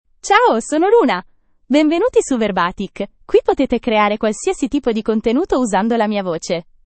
Luna — Female Italian (Italy) AI Voice | TTS, Voice Cloning & Video | Verbatik AI
Luna is a female AI voice for Italian (Italy).
Voice sample
Listen to Luna's female Italian voice.
Female
Luna delivers clear pronunciation with authentic Italy Italian intonation, making your content sound professionally produced.